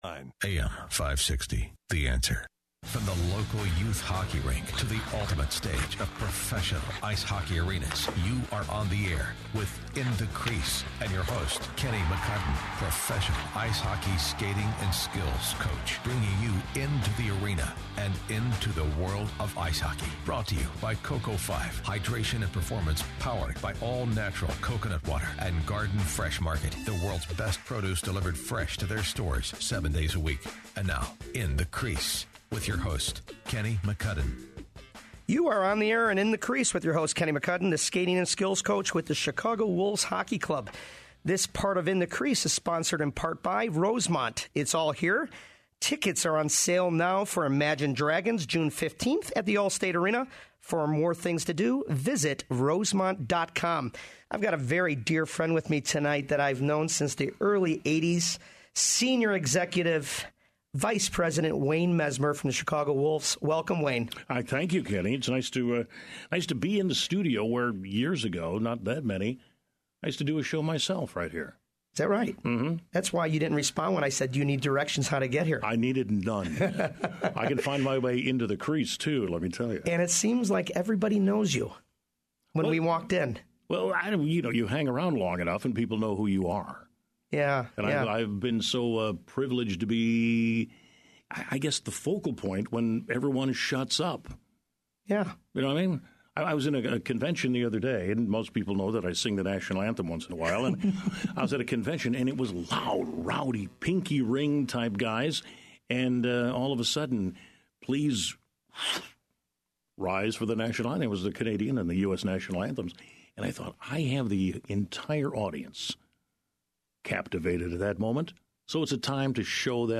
Radio Interview AM560 The Answer